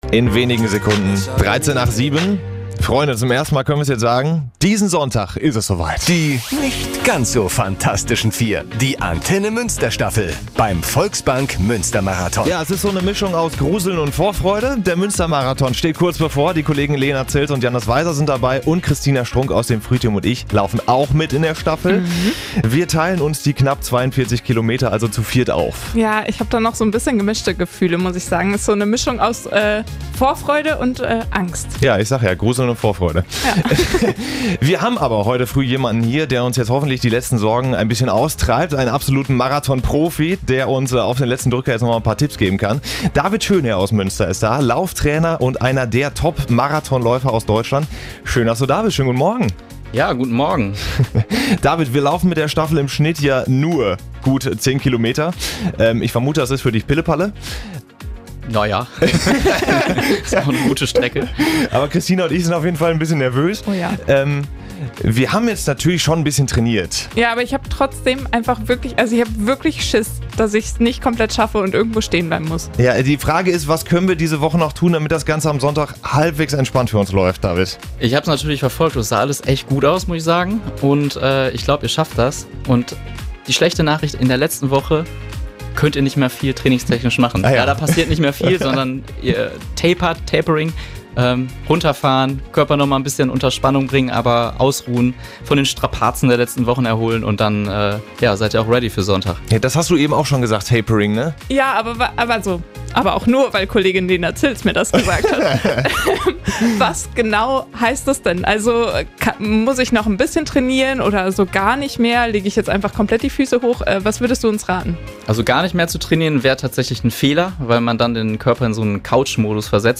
Er läuft selbst am Sonntag den Marathon und hat uns heute Morgen im Studio besucht. Dabei erzählt er, worauf wir in der letzten Woche vor unserem Staffellauf achten sollten.